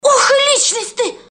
голосовые